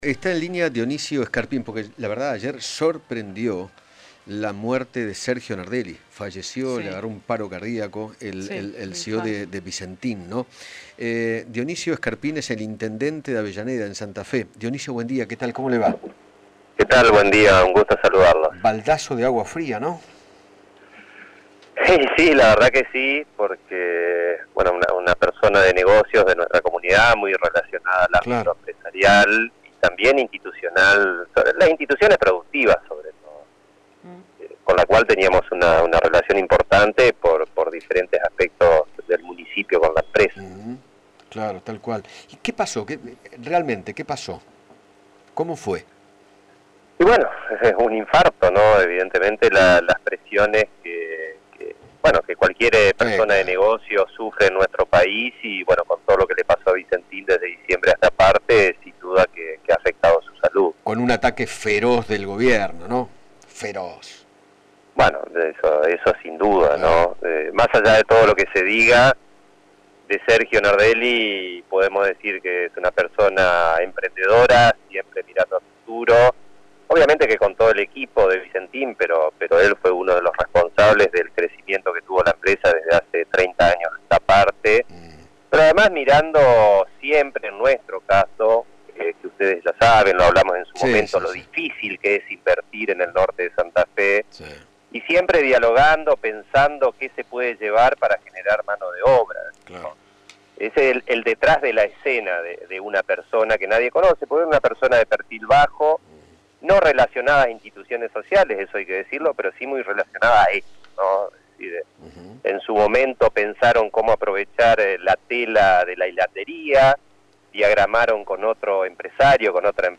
Dionisio Scarpin, Intendente de Avellaneda, Santa Fe, dialogó con Eduardo Feinmann sobre el fallecimiento del CEO de Vicentín, Sergio Nardelli, nieto del fundador de la empresa agroexportadora, a causa de un ataque cardíaco.